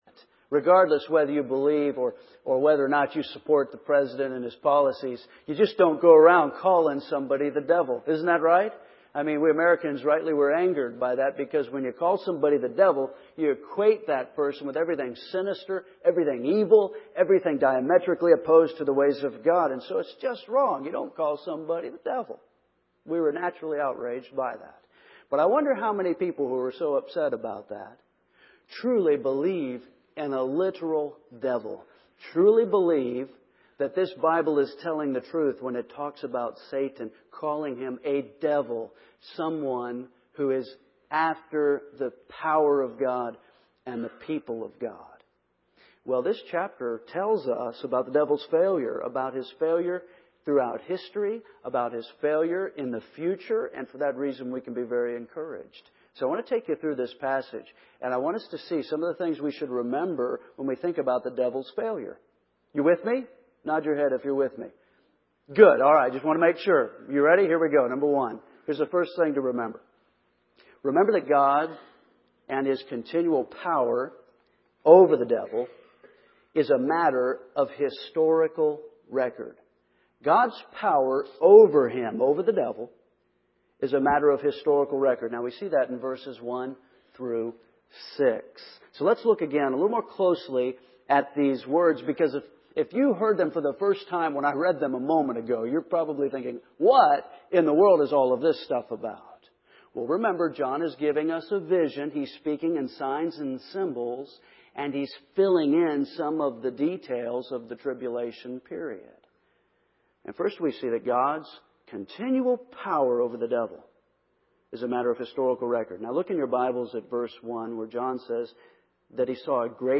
First Baptist Church, Henderson KY